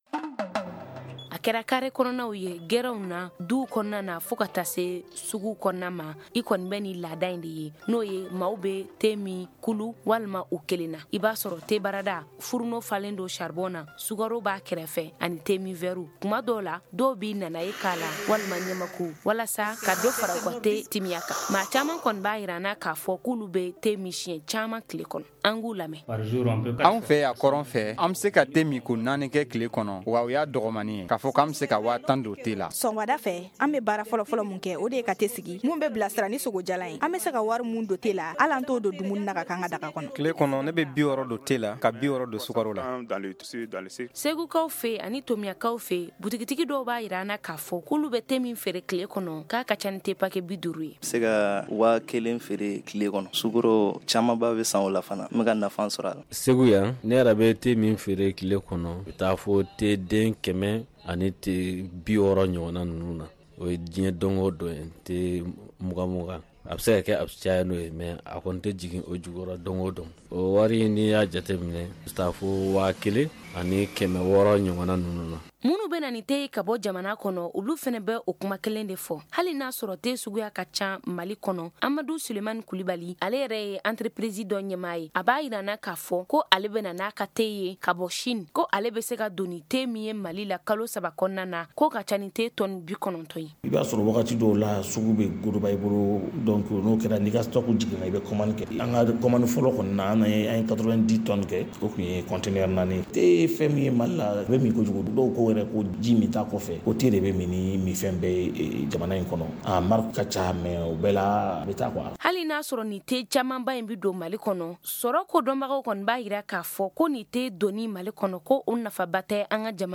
magazine économie